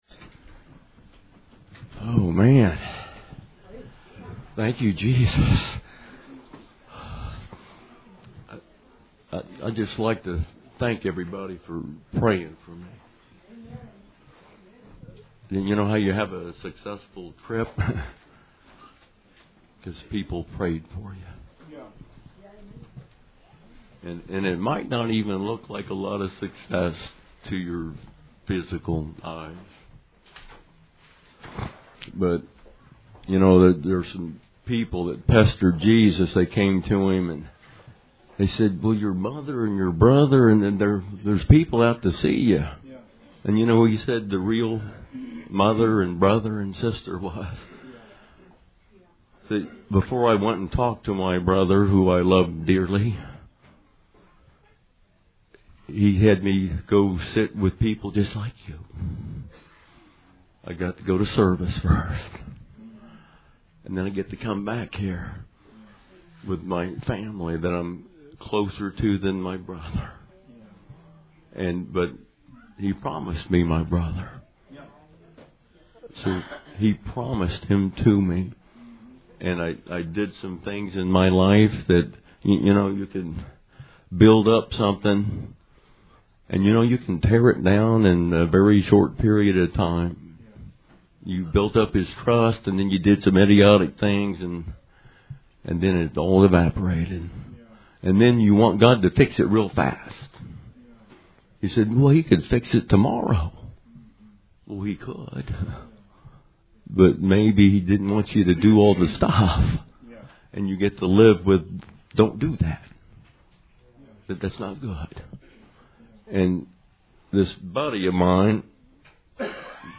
Sermon 2/4/18